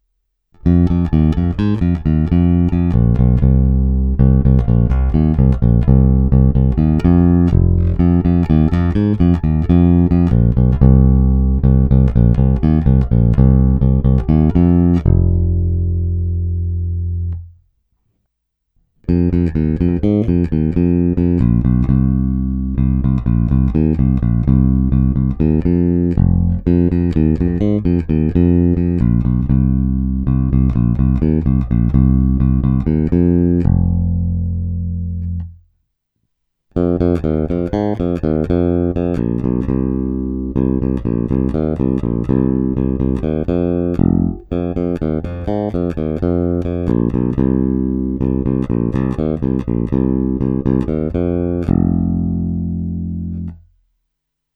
Zvuk je variabilní, plný, čitelný, má ty správné středy, díky kterým se prosadí v kapelním zvuk a taky jej pěkně tmelí.
Snímač u kobylky